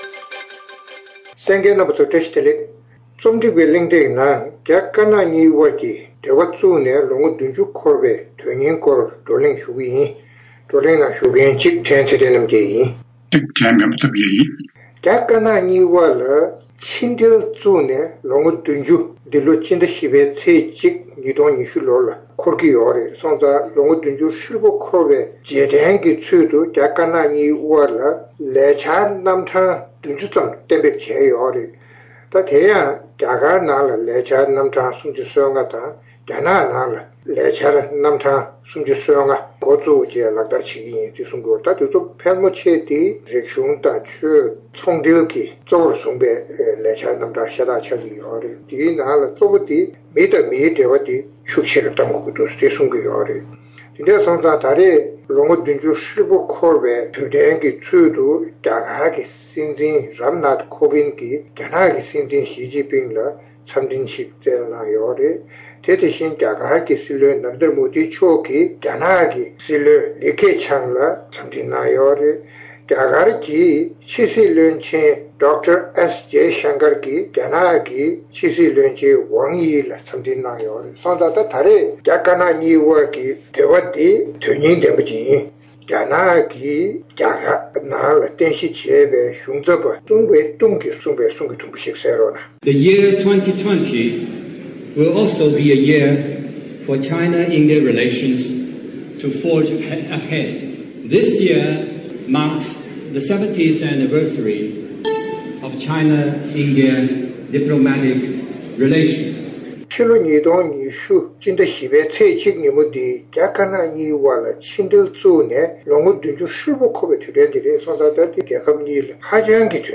རྒྱ་དཀར་ནག་གཉིས་དབར་ཕྱི་འབྲེལ་བཙུགས་ནང་ལོ་ངོ་བདུན་ཅུ་འཁོར་བའི་དོན་སྙིང་སྐོར་རྩོམ་སྒྲིག་འགན་འཛིན་རྣམ་པས་བགྲོ་གླེང་གནང་བ།